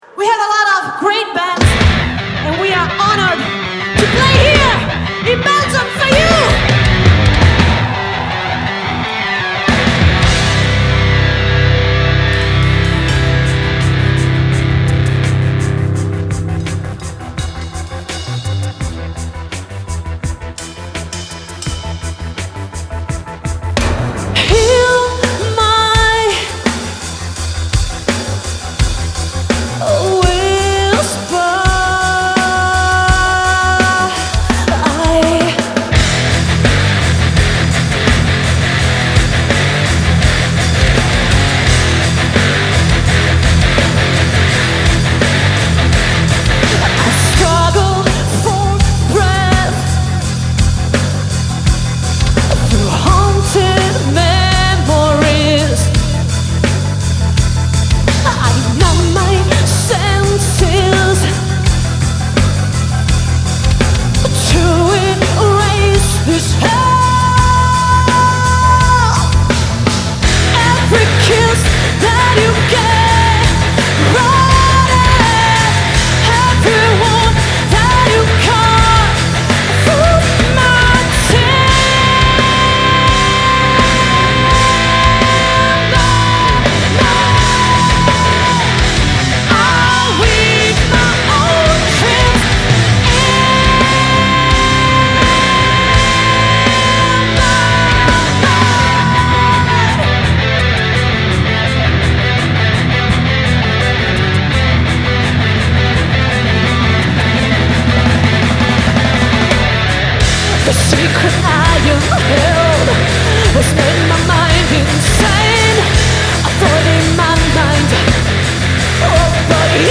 Vocals
Lead Guitars
Drums
Machines